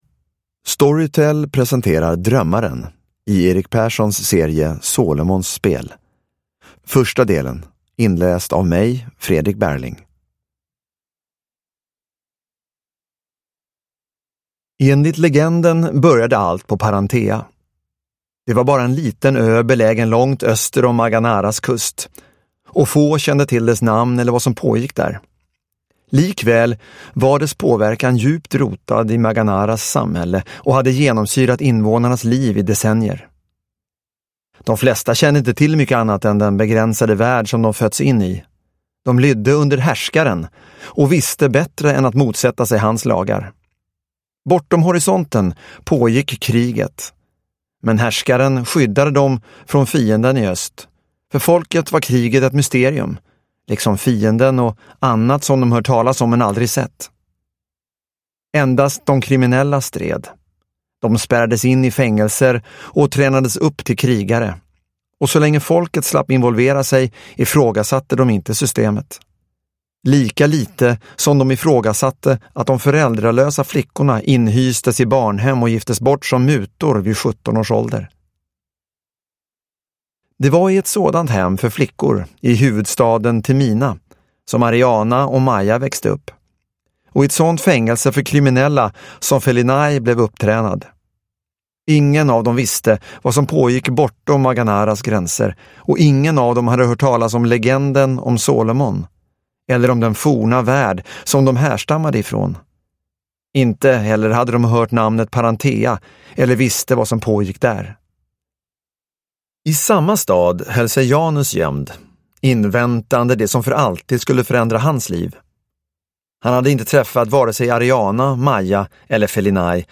Drömmaren - Del 1 – Ljudbok – Laddas ner